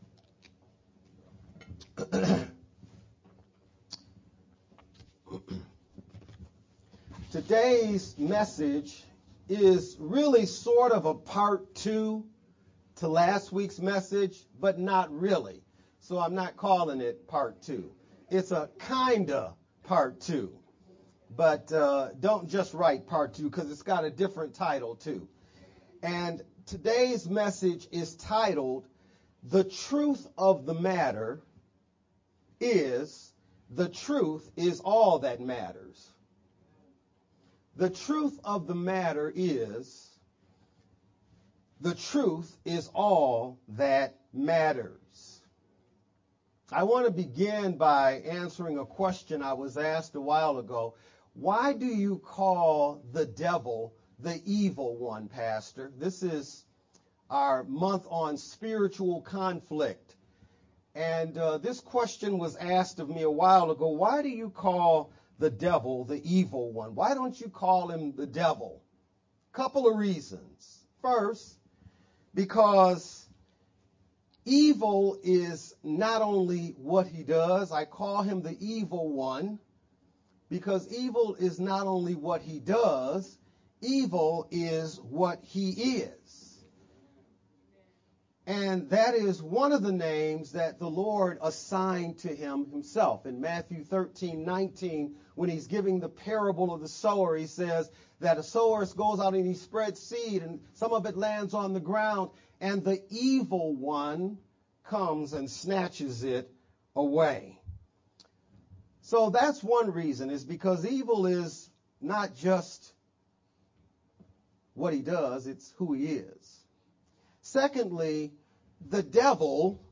July-9th-Sermon-only-_Converted-CD.mp3